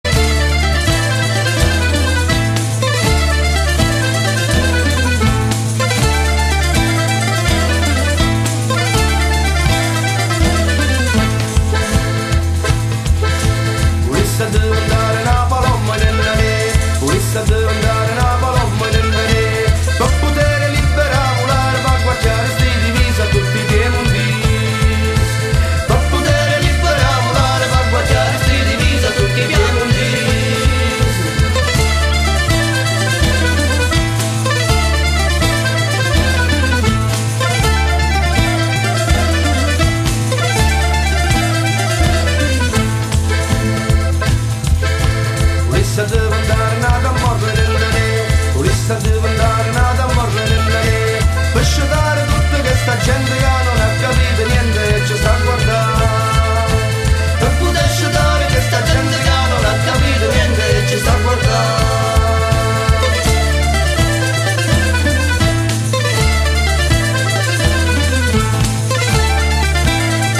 Voce e Chitarra
Fisarmonica
Sax e Clarinetto
Percussioni